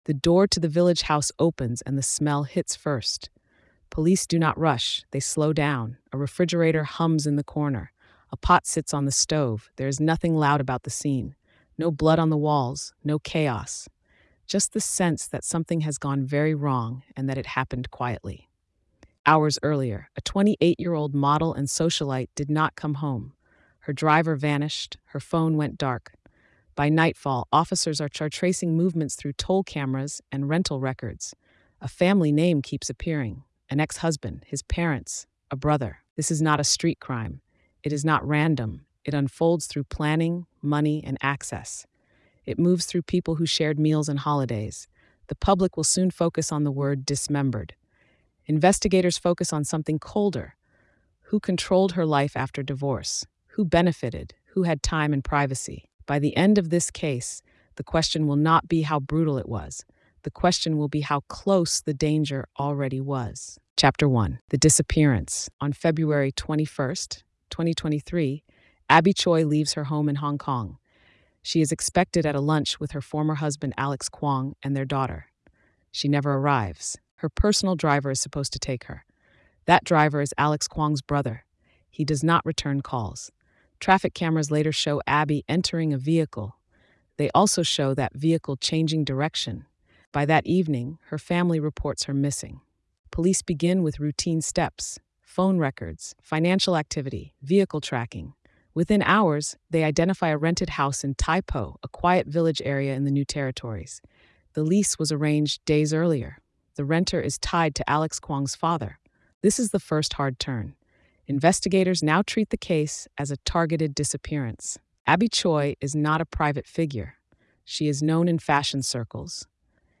The story follows investigators as a routine missing persons report turns into a homicide case involving an ex husband and his relatives. Told with a gritty but factual tone, it focuses on evidence, timelines, and betrayal rather than spectacle.